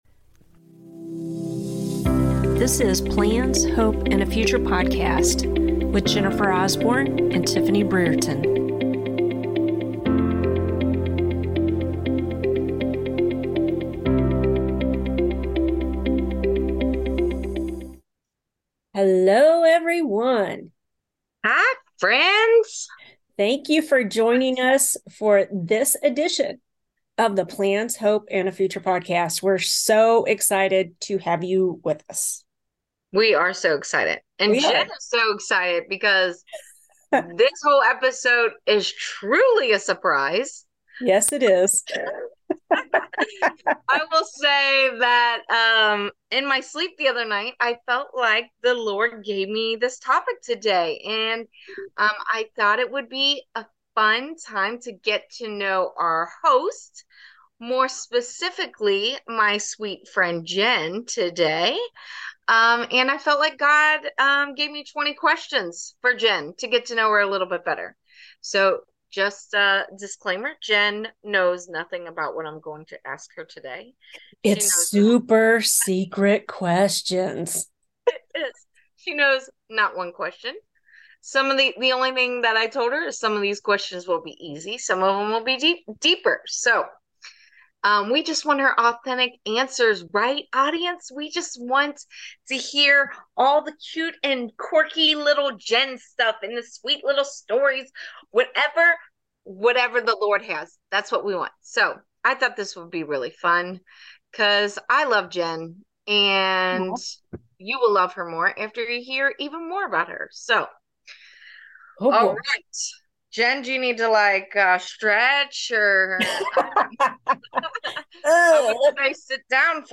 Warning...there is a lot of laughing.